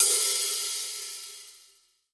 14HK OPEN.wav